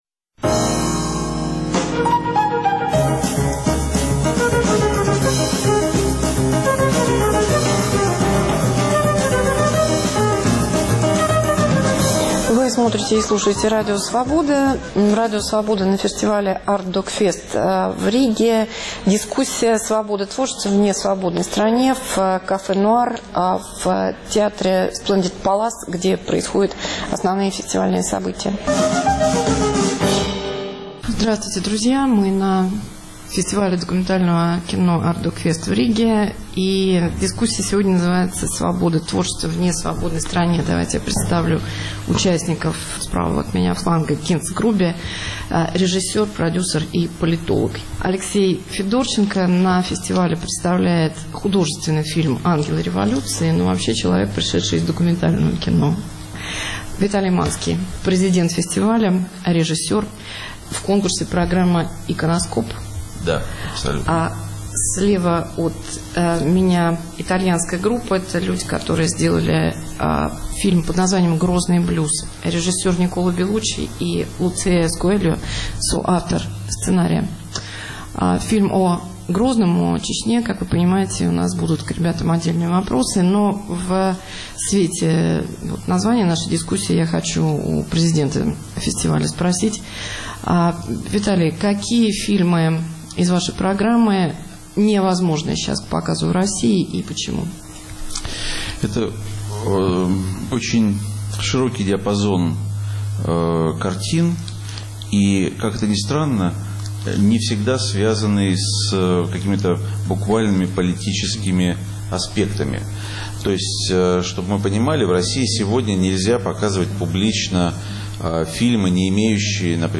На фестивале "Артдокфест" в Риге. С режиссерами документального кино - о работе в Чечне, Северной Корее, России. Может ли документальное кино противостоять пропаганде?